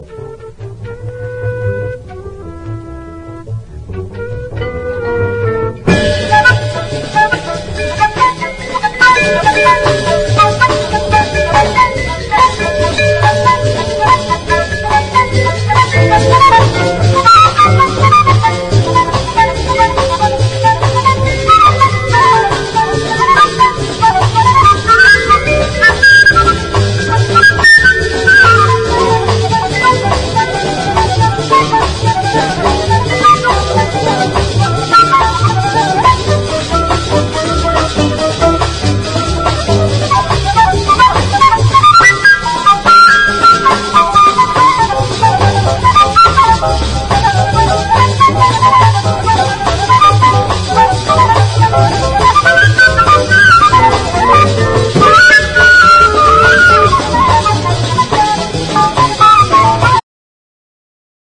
フルートとピアノの絡みが超カッコいいモッド・ジャズな名曲